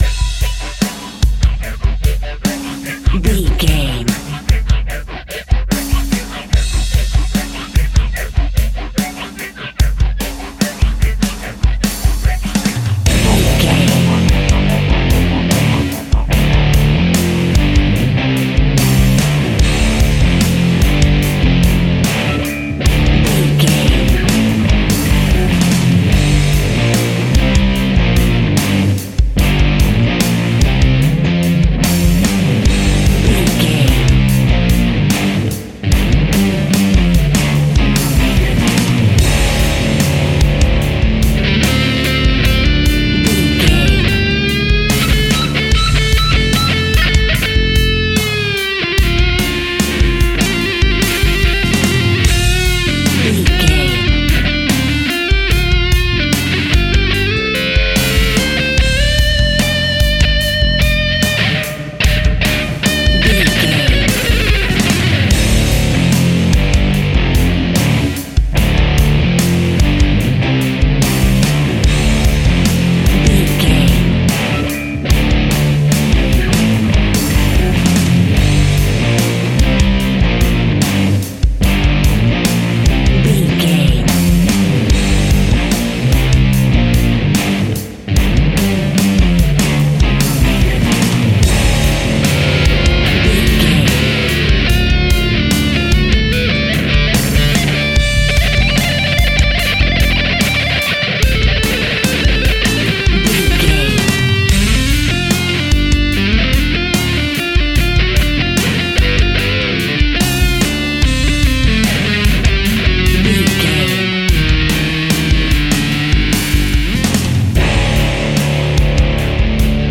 Powerful Rock Music Cue Full Mix.
Epic / Action
Fast paced
Aeolian/Minor
heavy metal
rock instrumentals
Rock Bass
heavy drums
distorted guitars
hammond organ